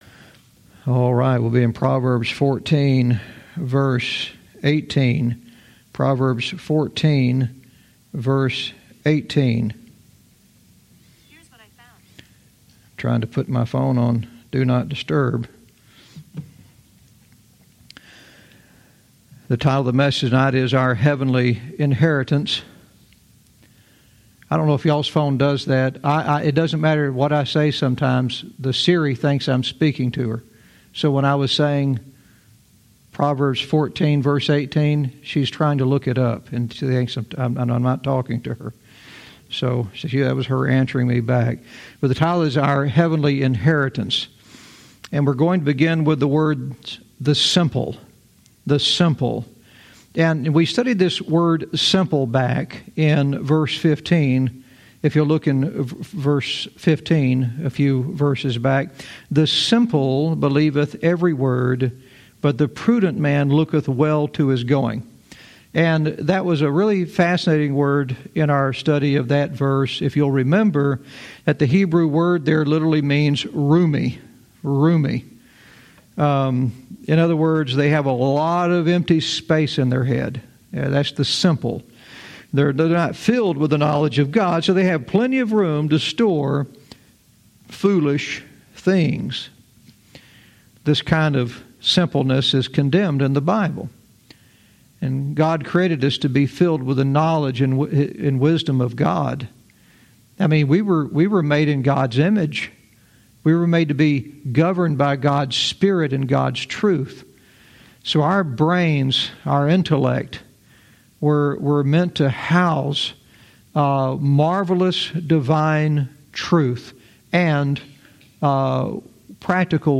Verse by verse teaching - Proverbs 14:18 "Our Heavenly Inheritance"